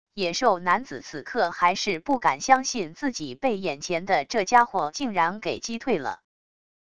野兽男子此刻还是不敢相信自己被眼前的这家伙竟然给击退了wav音频生成系统WAV Audio Player